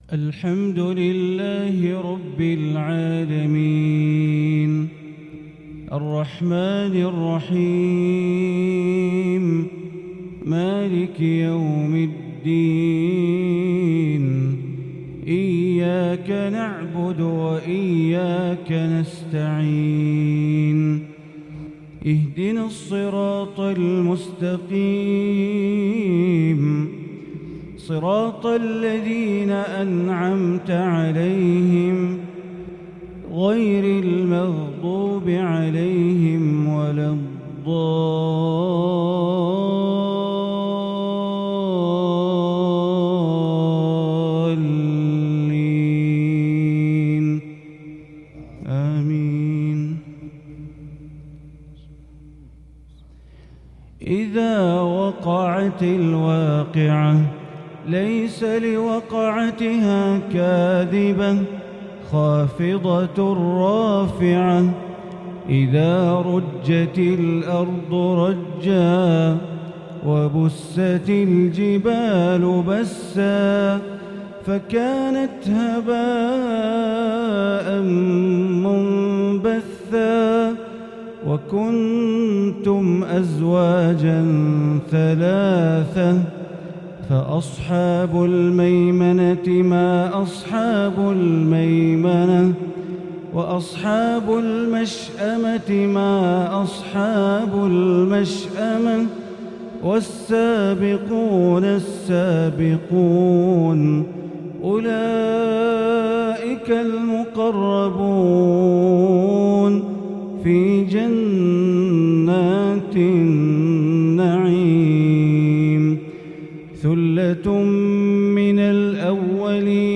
Fajr prayer from Surat Al-Waqia 22/9/2021 > 1443 > Prayers - Bandar Baleela Recitations